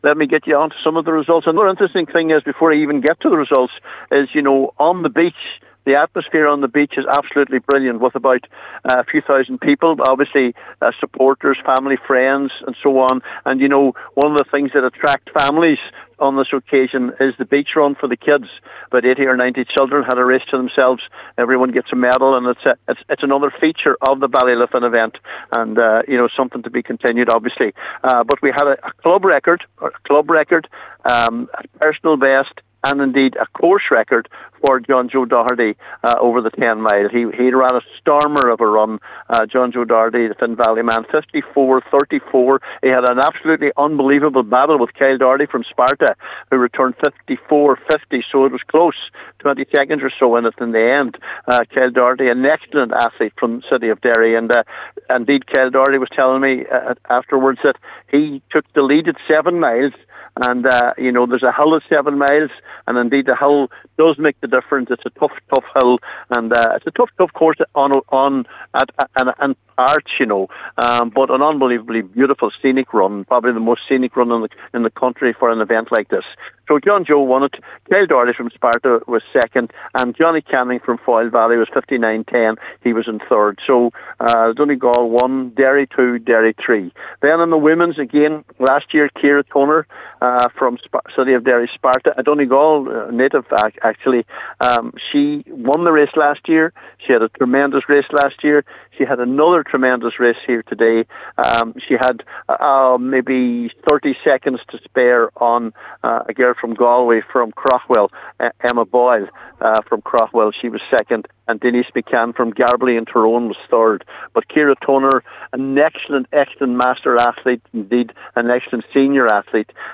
gave us his report after the event…